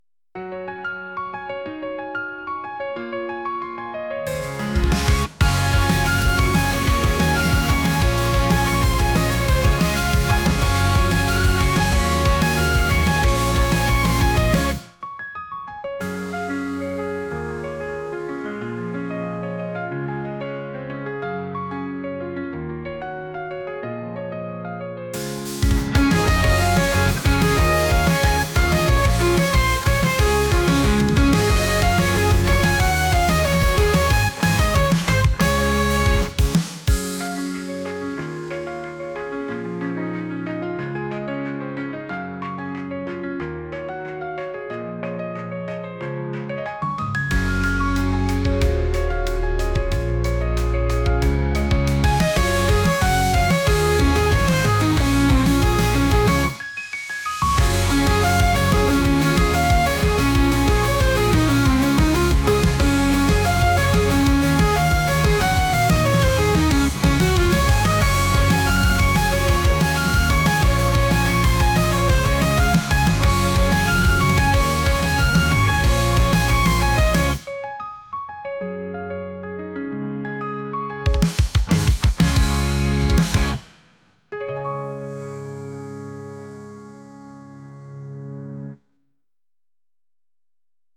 ボーイミーツガールアニメのOPっぽい音楽です。